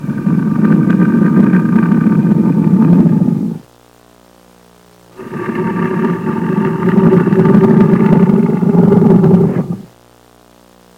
Tiger sound